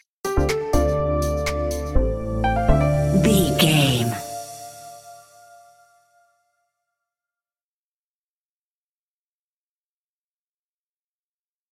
Modern Chart Pop Electro Music Stinger.
Aeolian/Minor
D
groovy
uplifting
driving
energetic
synthesiser
drum machine
electric piano
electronic